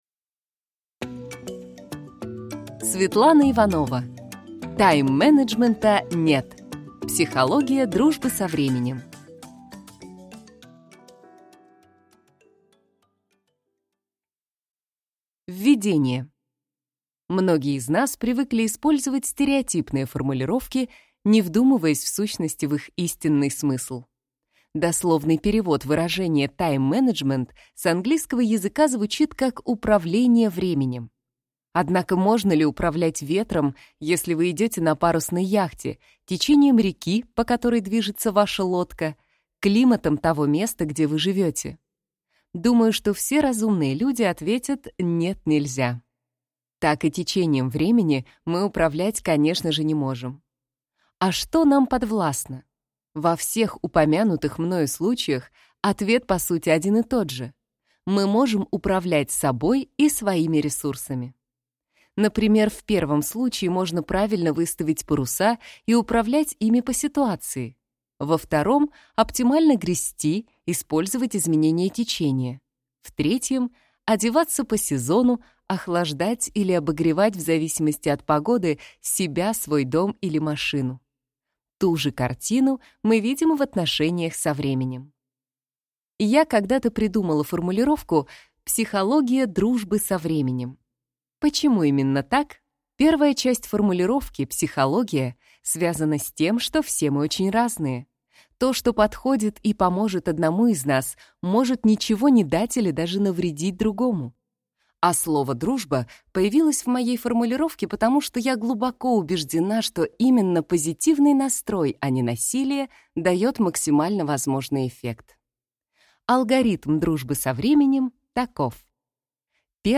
Аудиокнига Тайм-менеджмента нет. Психология дружбы со временем | Библиотека аудиокниг